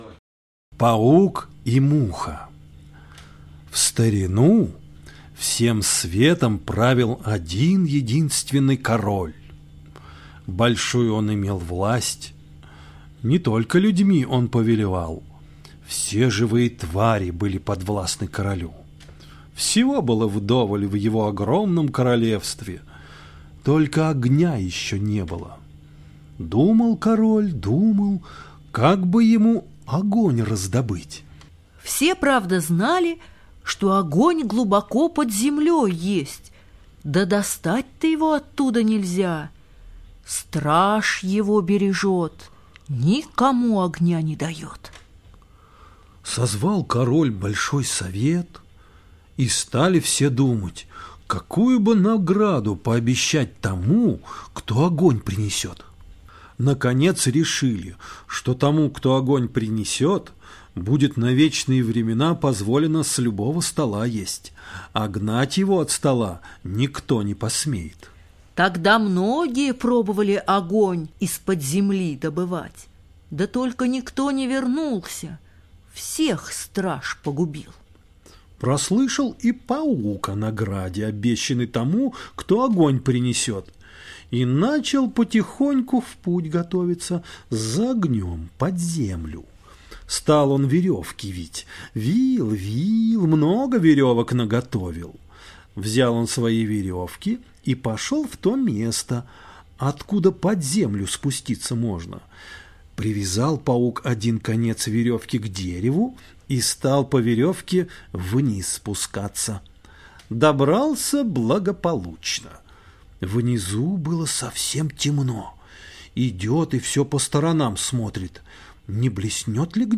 Паук и муха - латышская аудиосказка - слушать онлайн